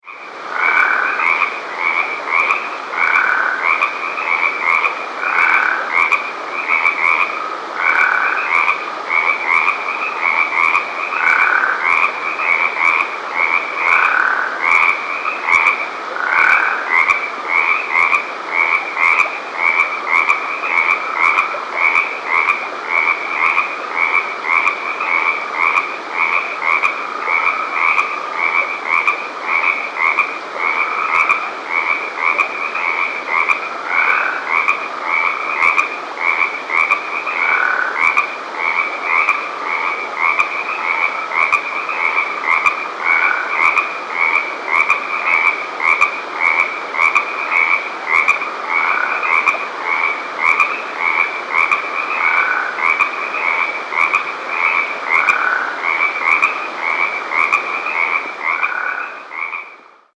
The call of the Western Spadefoot is a short loud trill, like a quick snore, lasting less than one second.
The following recordings were made at night in mid March at a distance of 200 - 300 ft. from a creek flowing through Alameda and San Joaquin Counties. Sierran Treefrogs are heard in the background.
Sound  This is a 60 second recording the advertisement calls of a small group of Western Spadefoots made in Alameda County.